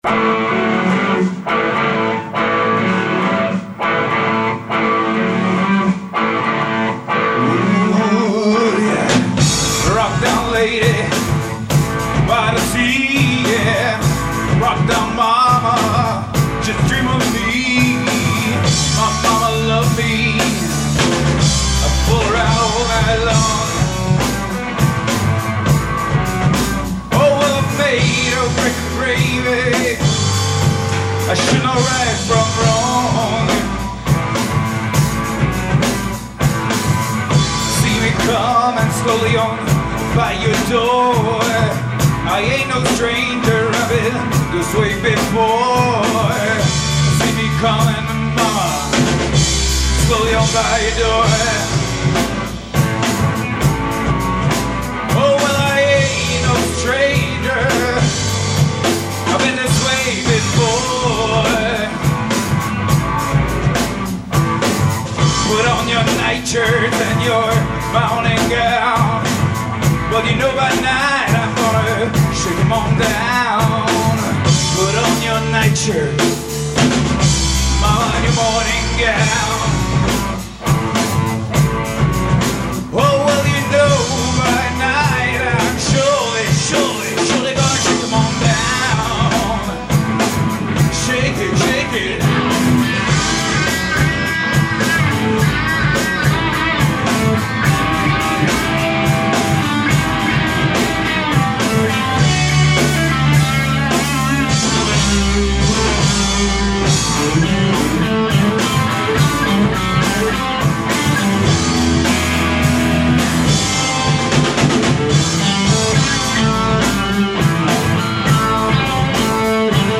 en r�p�t' septembre 2002